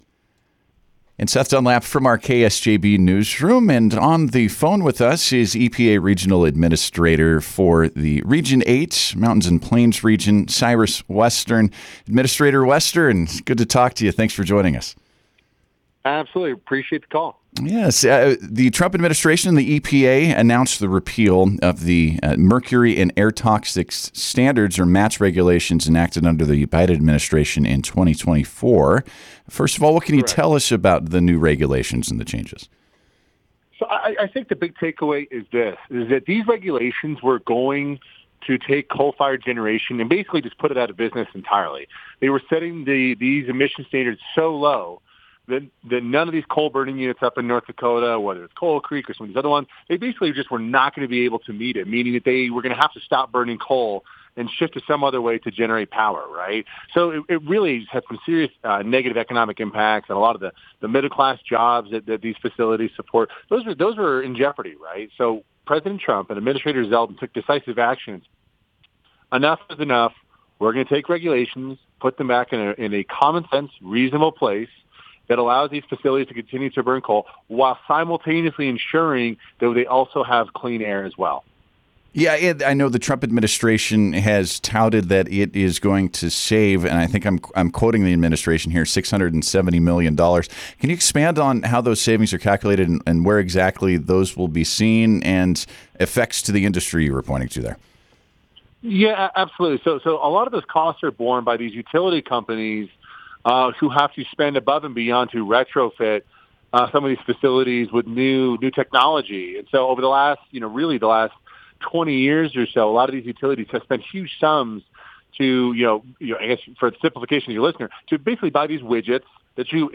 (EDITORS NOTE: Listen to the fully unedited interview at the link below the article.)
EPA Regional Administrator Cyrus Western joined KSJB Radio to discuss the repeal of the MATS regulations around coal power, the approval of the North Dakota’s Combustion Residuals Program, and the future of wind power in the state.